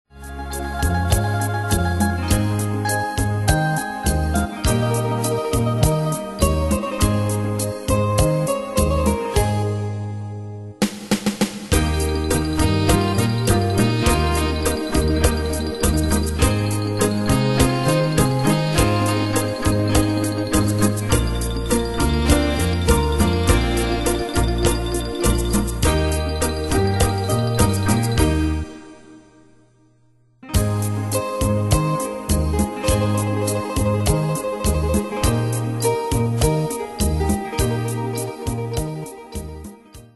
Style: Latino Ane/Year: 1967 Tempo: 102 Durée/Time: 3.38
Danse/Dance: Cumbia Cat Id.
Pro Backing Tracks